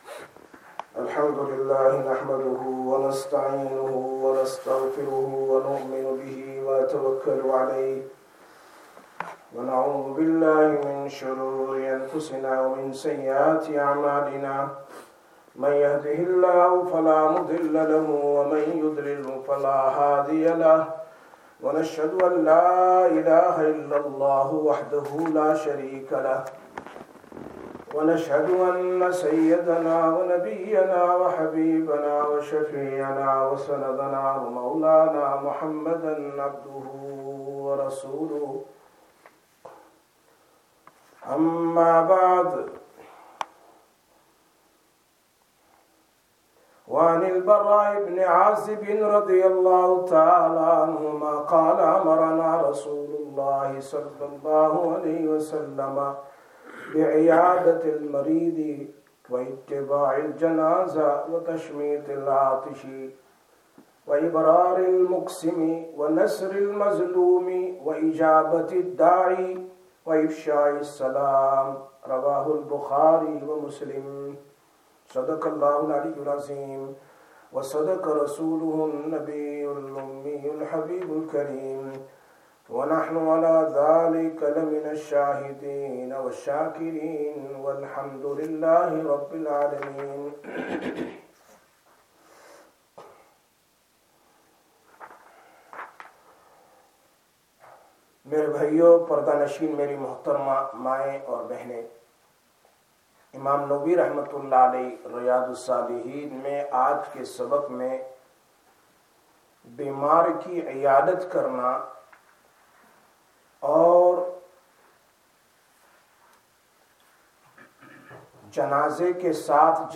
25/10/2023 Sisters Bayan, Masjid Quba